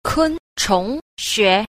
9. 昆蟲學 – kūnchóng xué – côn trùng học